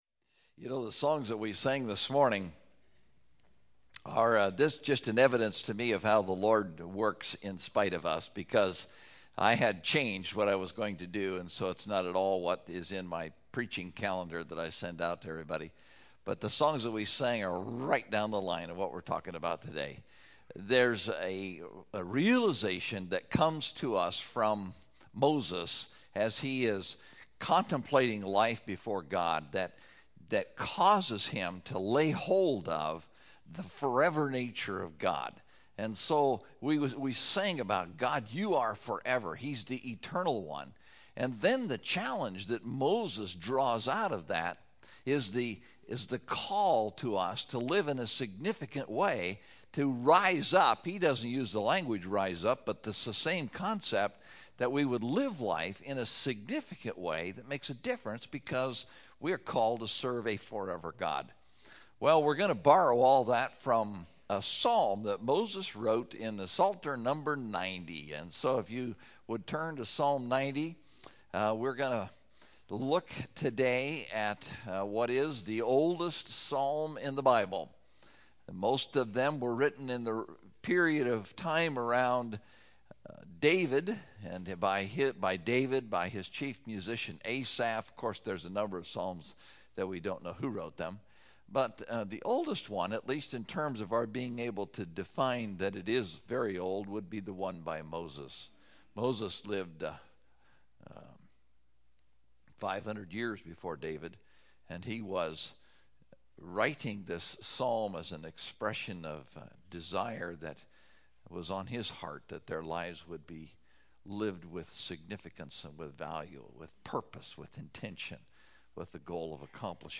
Topical Message